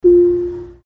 SFX – AMAZON ECHO – CANCEL ECHO SHOW POWER OFF
SFX-AMAZON-ECHO-CANCEL-ECHO-SHOW-POWER-OFF.mp3